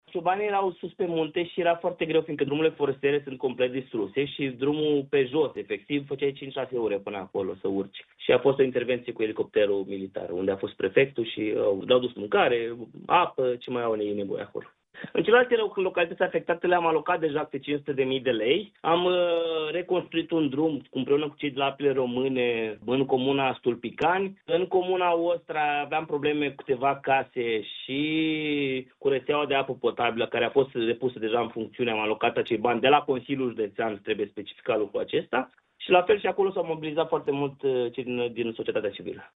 Gheorghe Șoldan a transmis pentru Radio Iași că așteaptă în continuare sprijin de la Guvern, pentru restabilirea infrastructurii.